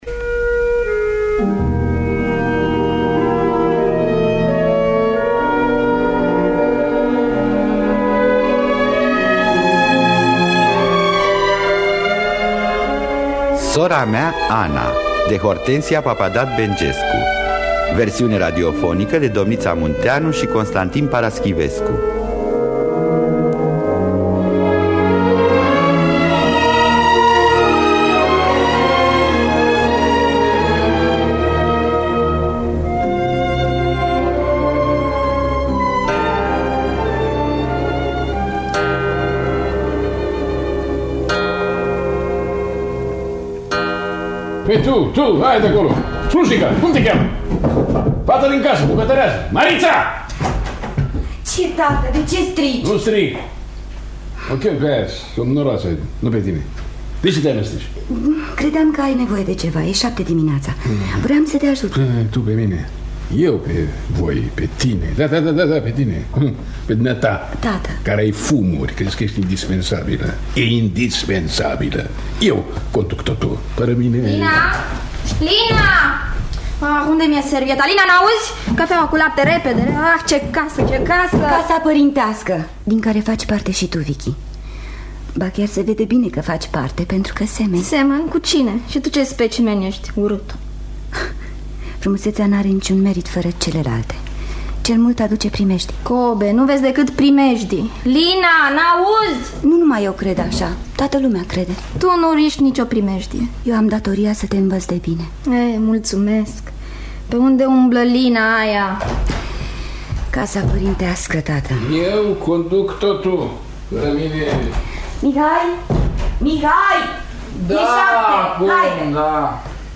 Dramatizare